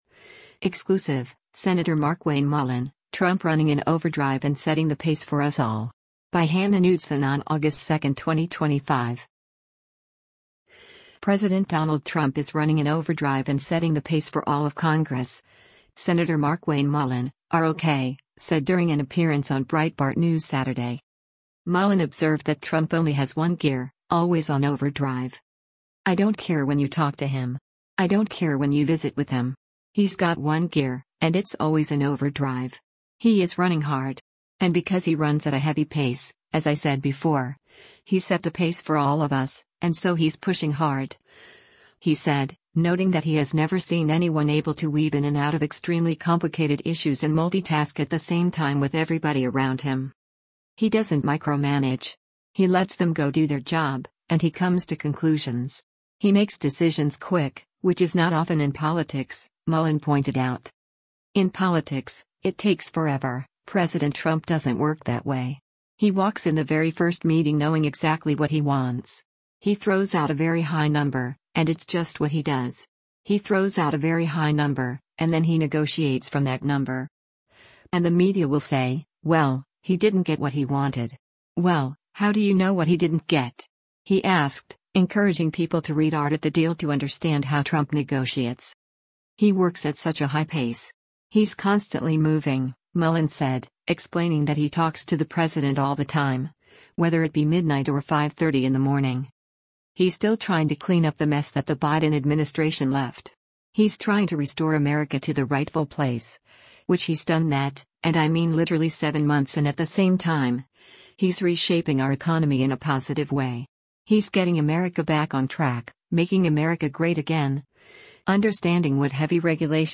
President Donald Trump is running in “overdrive” and setting the pace for all of Congress, Sen. Markwayne Mullin (R-OK) said during an appearance on Breitbart News Saturday.